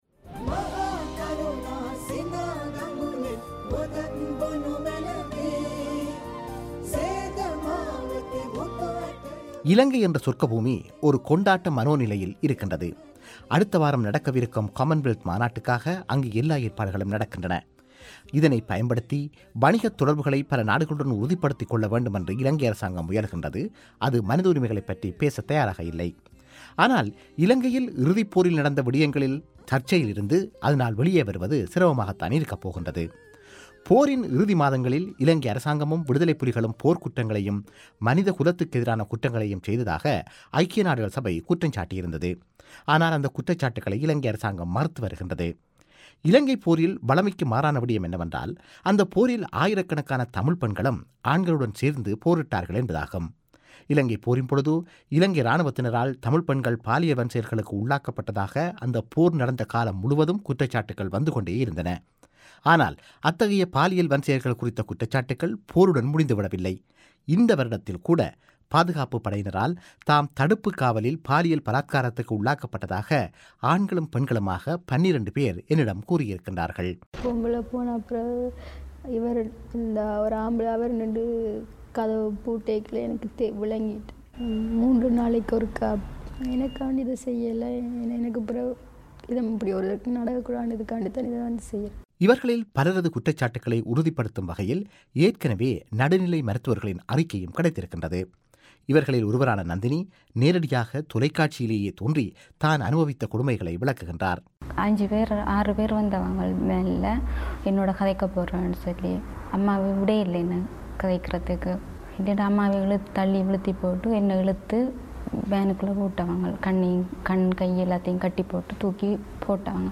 அது குறித்த ஆங்கில காணொளியின் தமிழ் ஒலி வடிவத்தை நேயர்கள் இங்கு கேட்கலாம்.